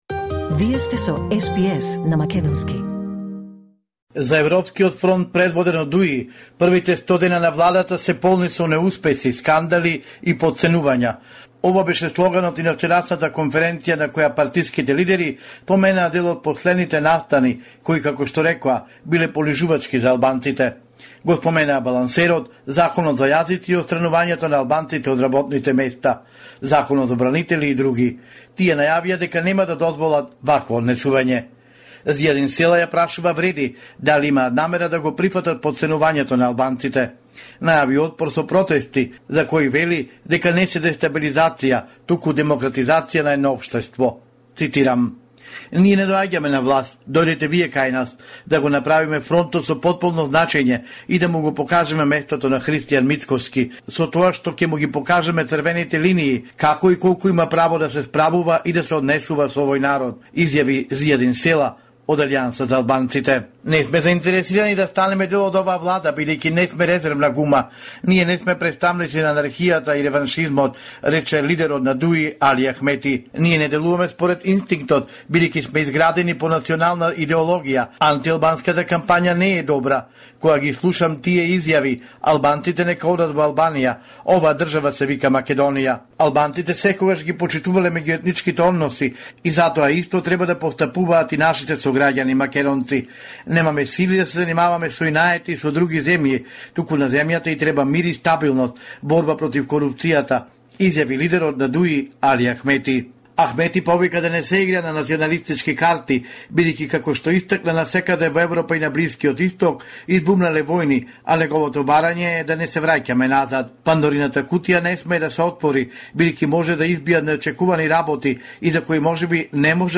Homeland Report in Macedonian 3 October 2024